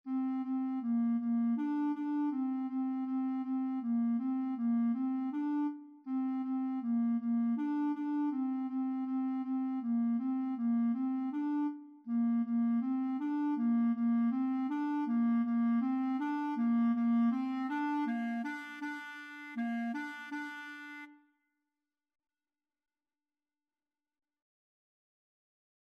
2/4 (View more 2/4 Music)
Bb4-D5
Clarinet  (View more Beginners Clarinet Music)
Classical (View more Classical Clarinet Music)